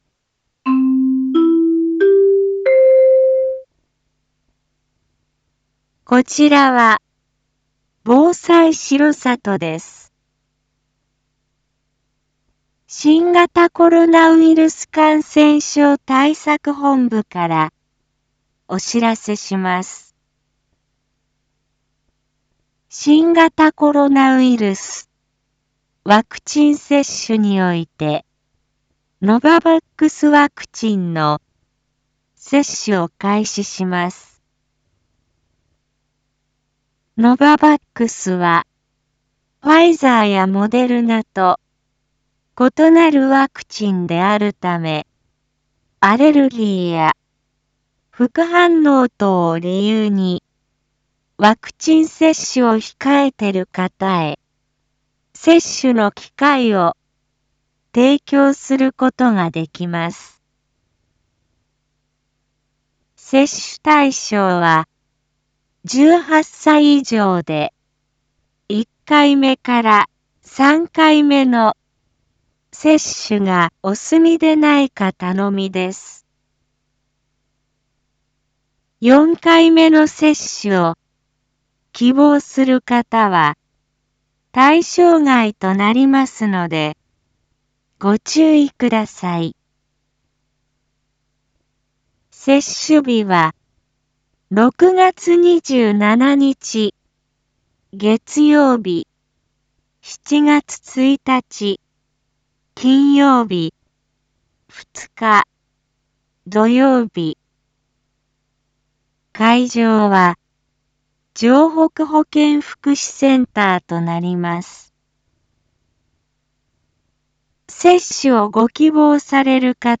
一般放送情報
Back Home 一般放送情報 音声放送 再生 一般放送情報 登録日時：2022-06-17 07:02:50 タイトル：新型コロナウイルスワクチン接種（ノババックス） インフォメーション：こちらは、防災しろさとです。